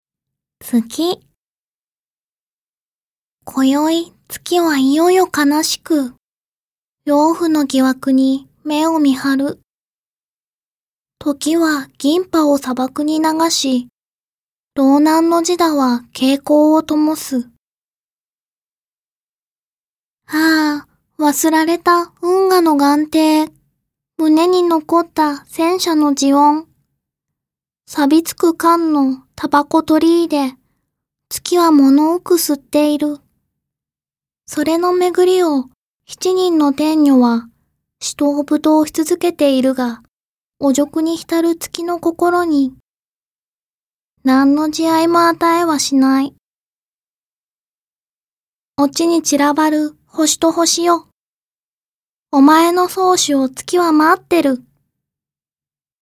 [オーディオブック] 萌えで読む！「山羊の歌」
中原中也本人が編纂した名作「山羊の歌」全詩を多数の萌え少女たちが朗読しています。
萌え少女たちが朗読することで、詩の新たな側面を感じる作品になっています。
有名な「サーカス」「汚れちまった悲しみに」、詩集のタイトルにもなった「山羊の歌」など、一度は聴いたことのある名作詩が、萌え少女たちの朗読によって、囁かれます。